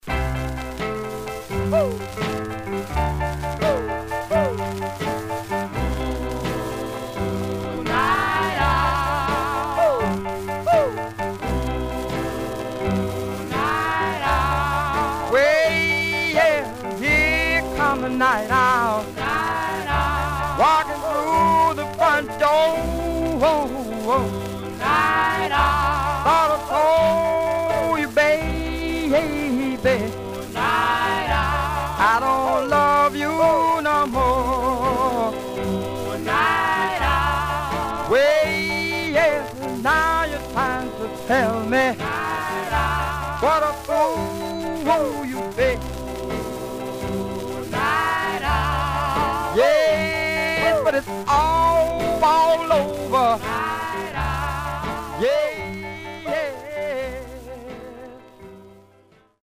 Stereo/mono Mono
Vocal Group